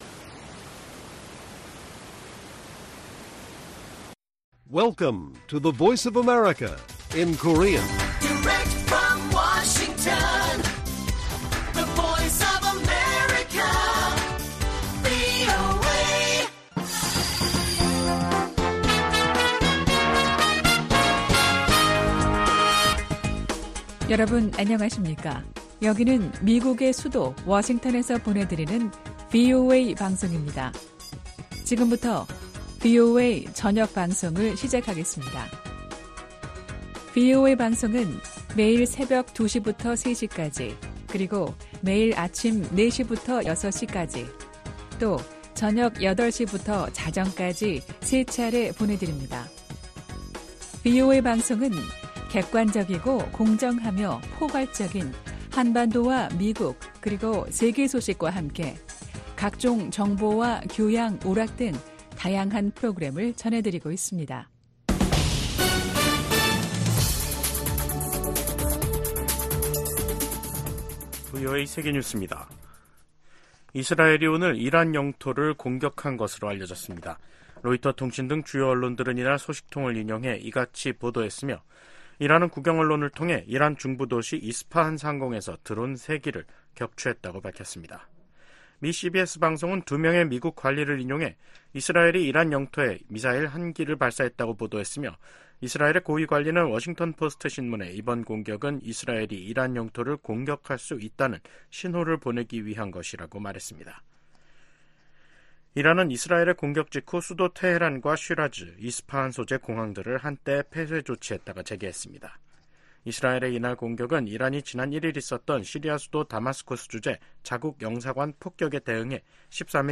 VOA 한국어 간판 뉴스 프로그램 '뉴스 투데이', 2024년 4월 19일 1부 방송입니다. 일본을 방문 중인 미국 유엔대사가 유엔 총회나 외부 기관 활용 등 대북제재 패널 활동의 대안을 모색하고 있다고 밝혔습니다. 미국이 한국과 우주연합연습을 실시하는 방안을 추진 중이라고 미국 국방부가 밝혔습니다.